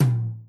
tom2.wav